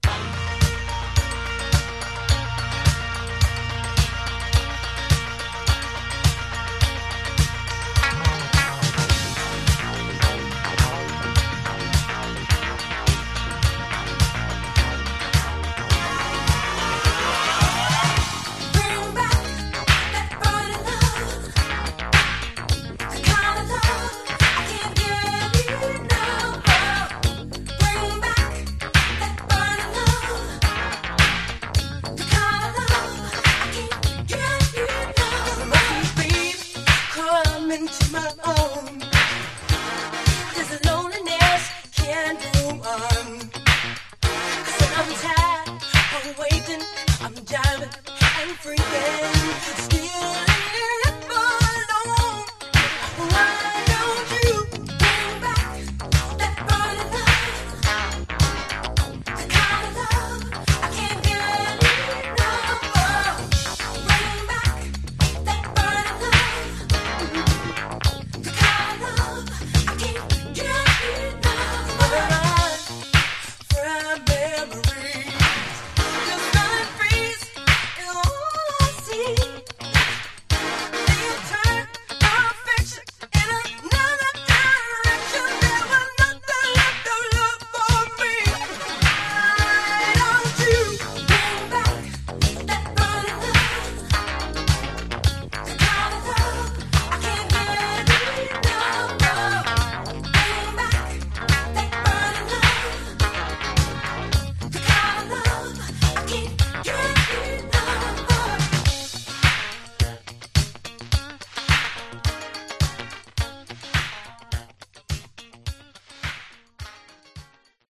Genre: Disco
This obscure Funk/Disco dancer is something special!